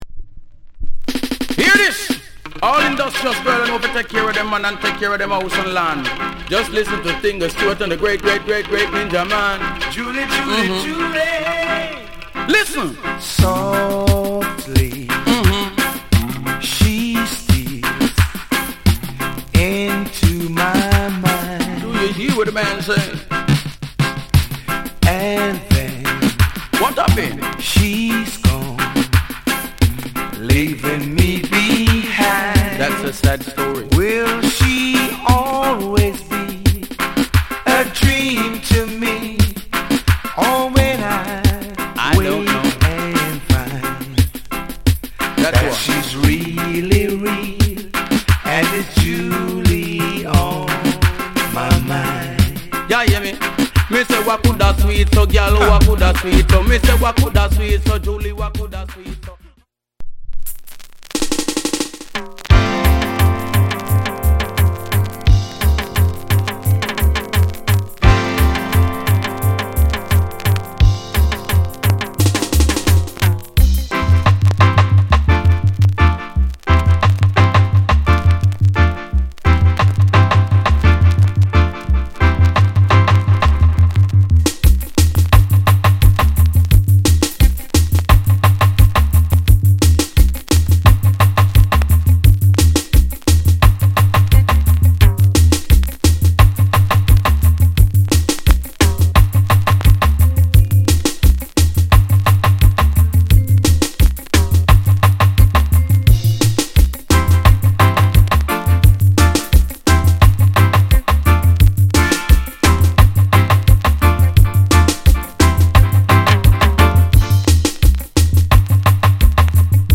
風トラック